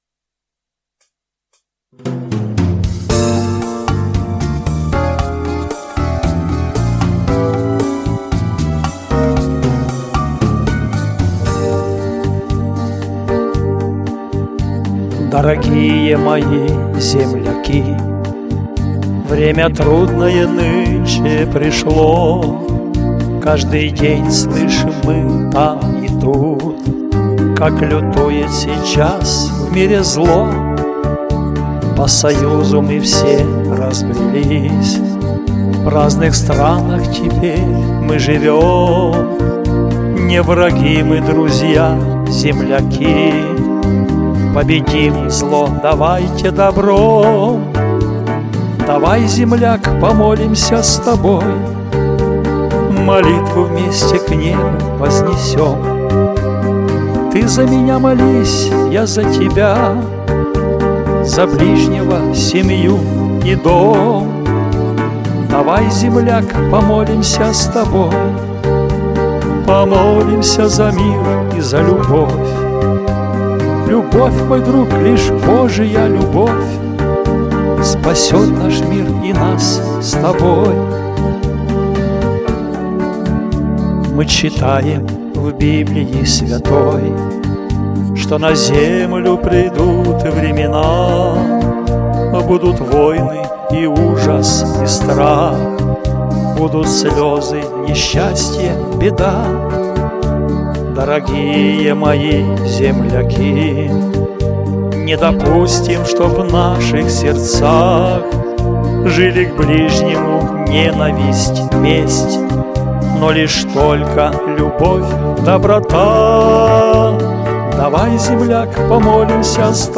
Христианские песни